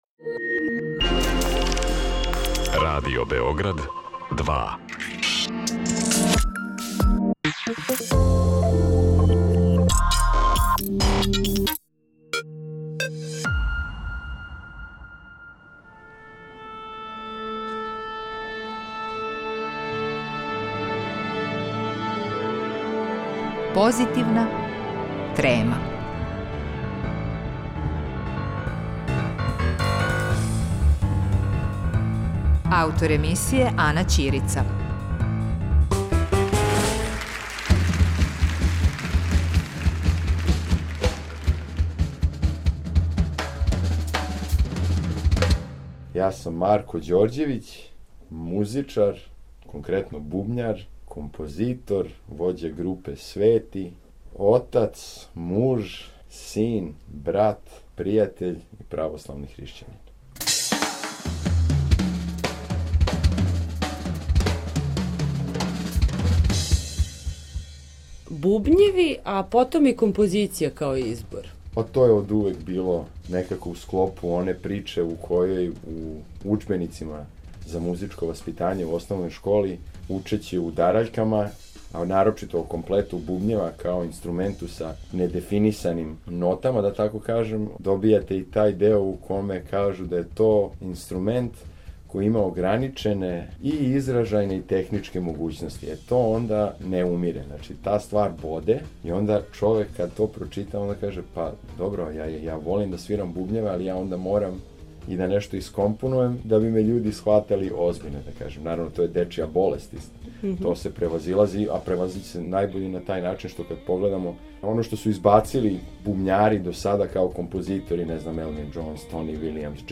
Разговарали смо у сусрет концерту Трија Свети